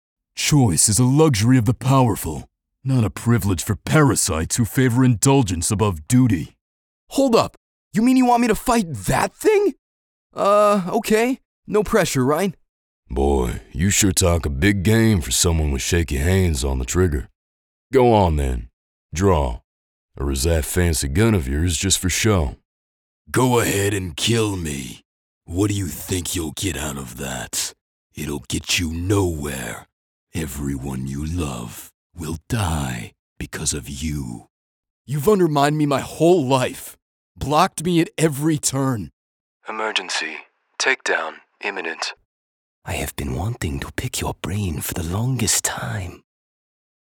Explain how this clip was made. Broadcast Quality Studio